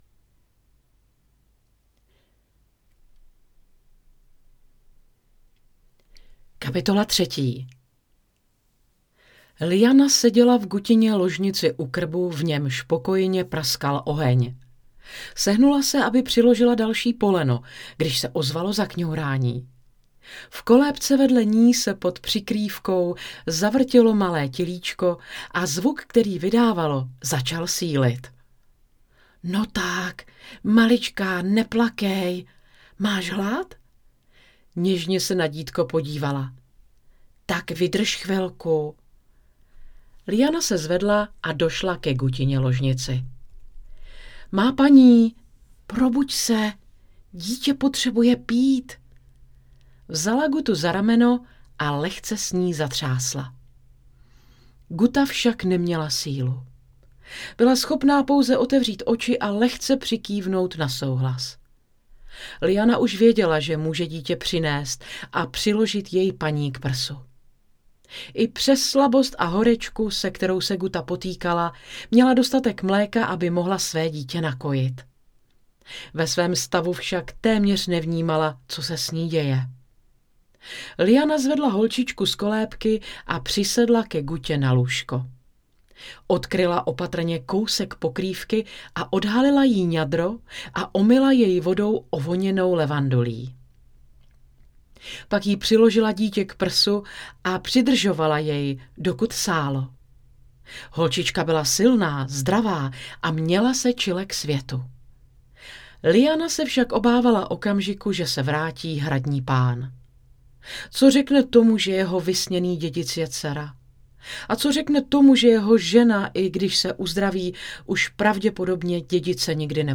Ženský hlas - voiceover/dabing
Nabízím hlasové služby v oblasti marketingu, reklamních spotů audio i vide, výroba komentářů k videům filmům, dabing kontinuální i stopáž k obrazu. Audio v domácím studiu, video přijedu podle potřeby.